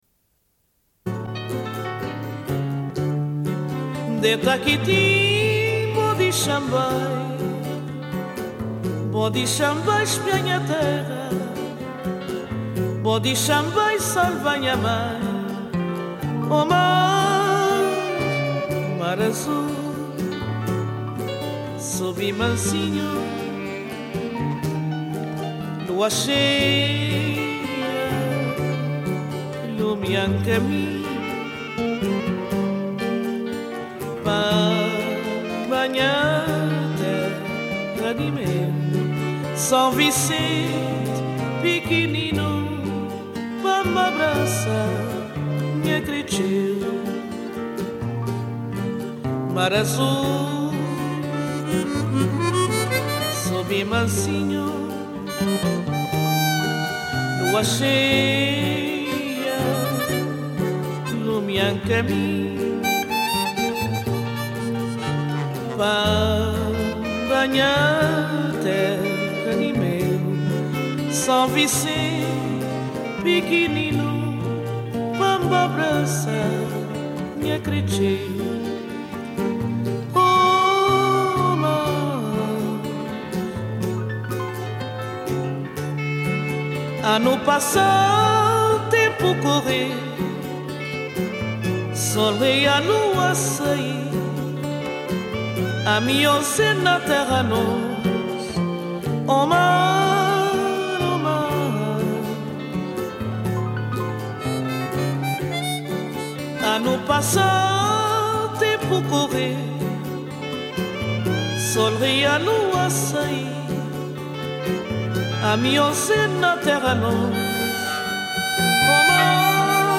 Une cassette audio, face B00:28:52
Suite de l'émission : au sujet du Festival de la Bâtie, édition 1992. Rencontre avec les femmes du comité du Festival. Rencontre avec deux comédien·nes de la troupe Mapap Teatro, au sujet de leur spectacle De Mortibus.